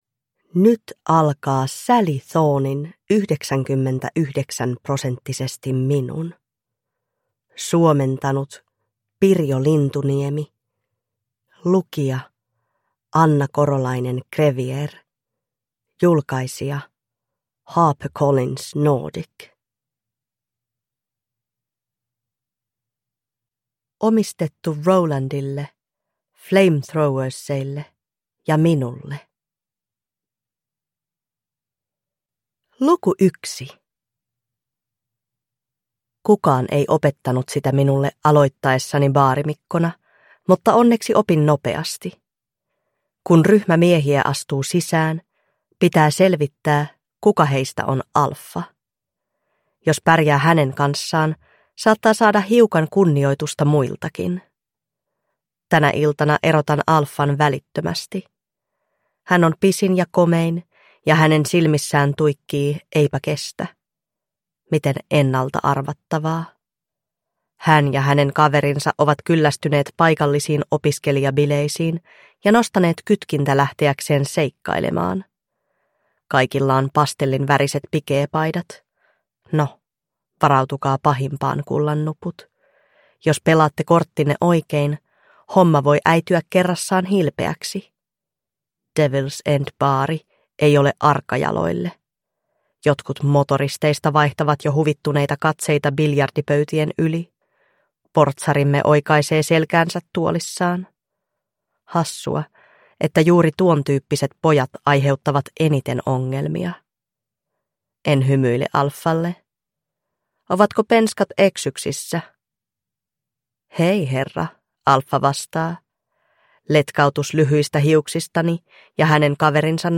99-prosenttisesti minun – Ljudbok – Laddas ner